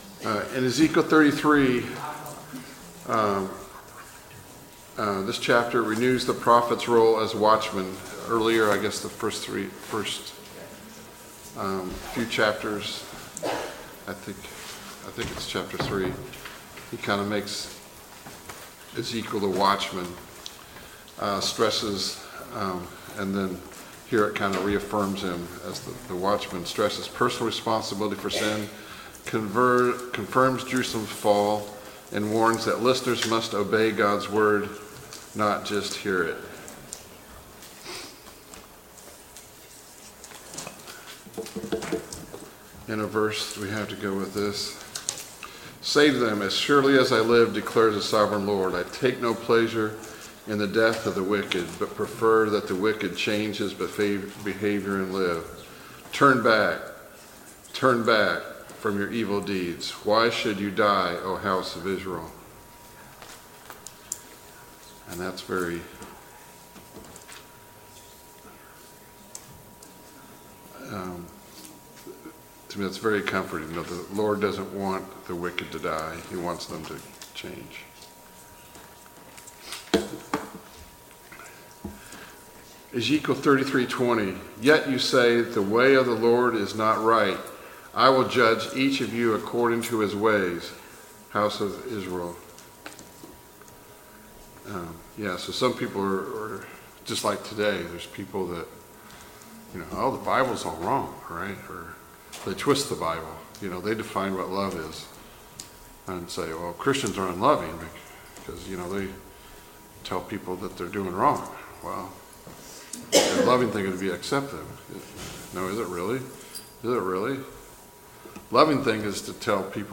Study of Ezekiel Service Type: Sunday Morning Bible Class « Study of Paul’s Minor Epistles